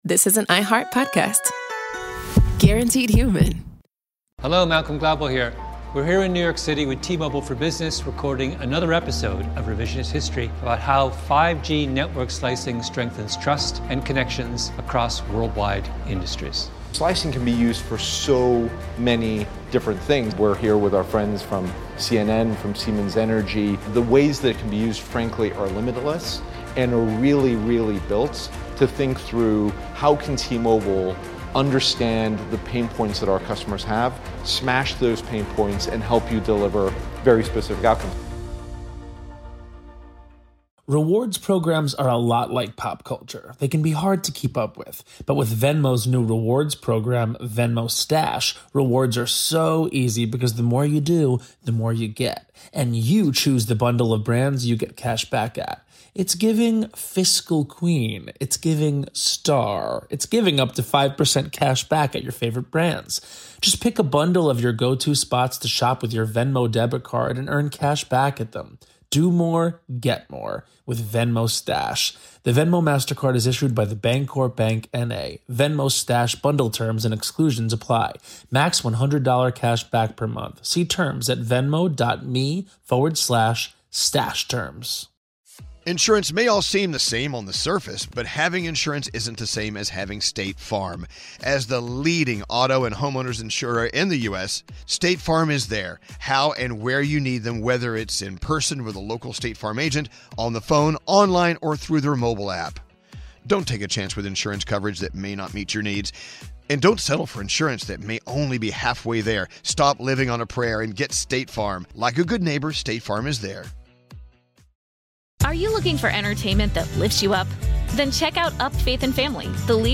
What began as a friendly dare among coon hunters grew into an annual mule-jumping competition that blends skill, stubbornness, and small-town pride. Locals, longtime competitors, and families who helped shape the tradition share how this unlikely sport took root and why it has endured.